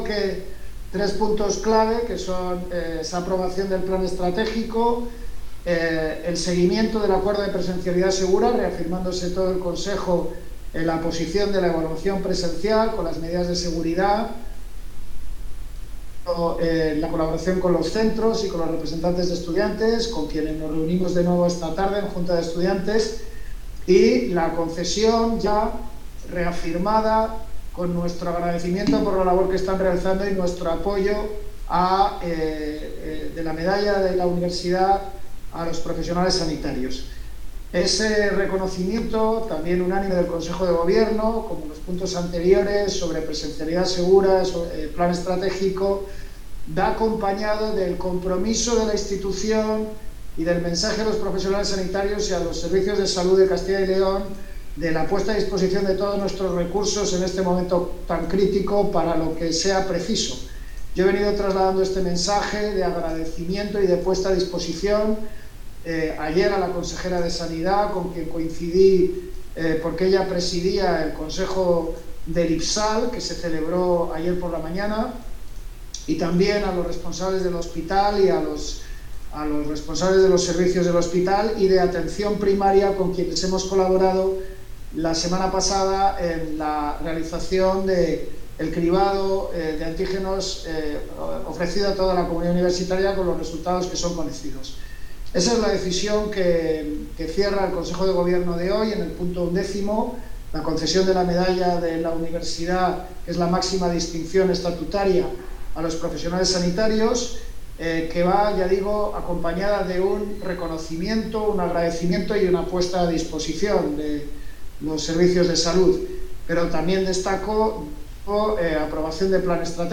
El rector también destacaba en la rueda de prensa on line, la aprobación del Plan Estratégico de la USAL y la reafirmación en las medidas de evaluación presencial segura.